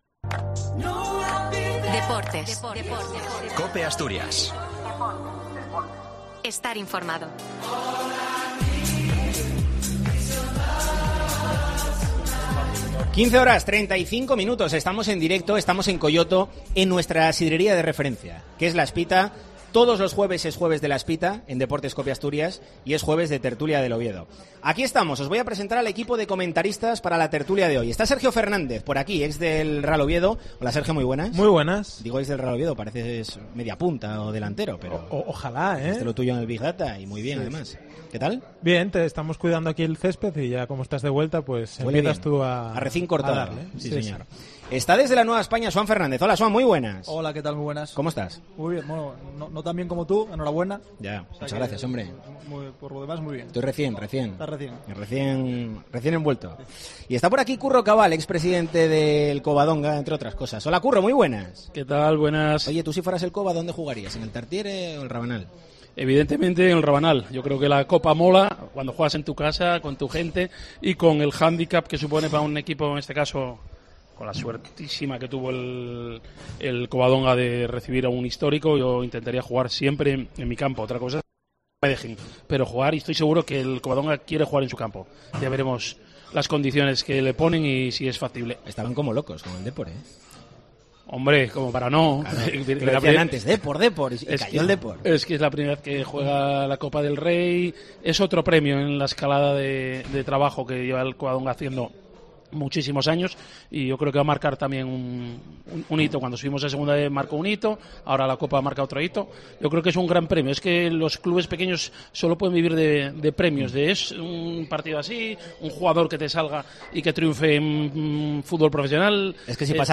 Deportes COPE Asturias vuelve a emitir en directo desde la sidrería La Espita